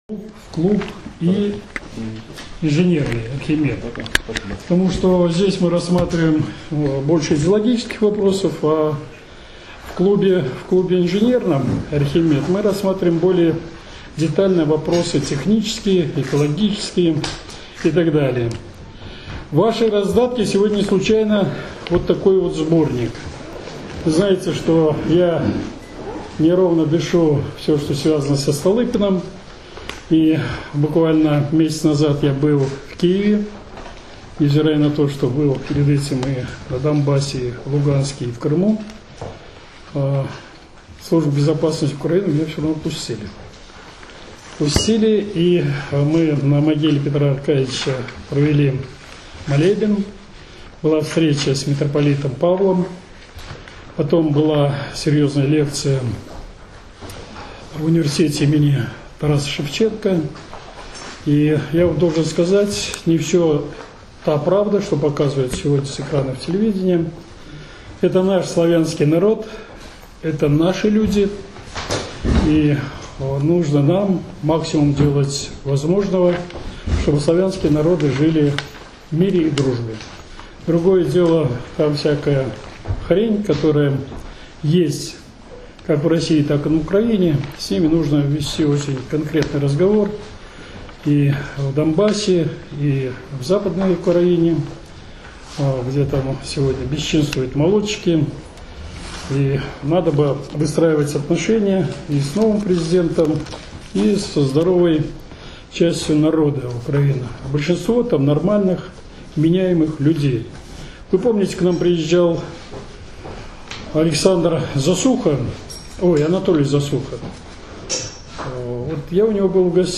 22 октября 2019 г. в Саратове состоялось очередное заседание регионального отделения Изборского клуба, на котором с большим докладом выступил его председатель, Президент Саратовского государственного технического университета, профессор Дмитрий Федорович Аяцков.